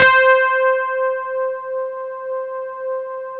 RHODES C4.wav